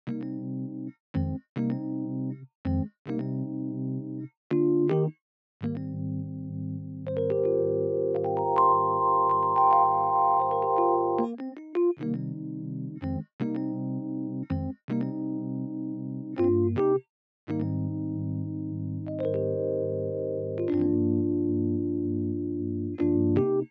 11 rhodes A.wav